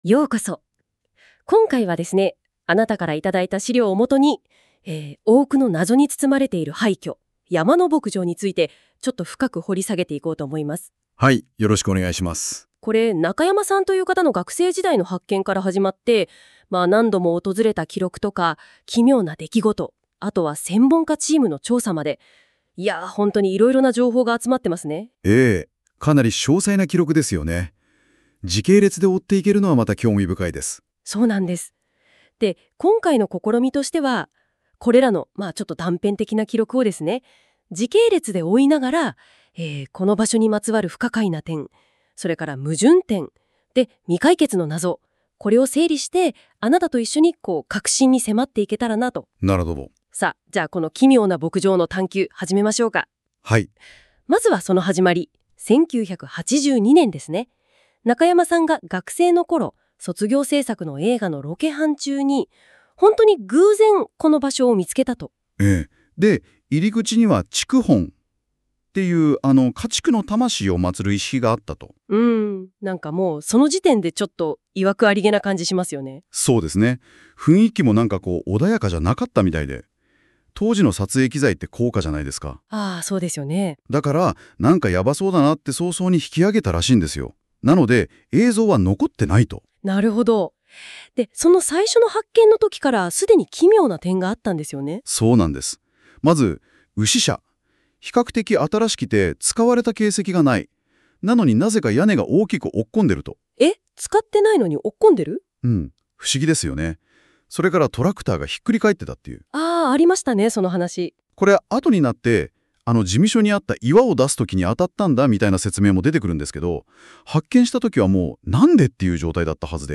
こちらは上記のスプレッドシートの内容をAIが音声解説したものです。
日本の最恐廃墟「山の牧場」未解決の超常ミステリー.mp3 ※ところどころ日本語がおかしいです。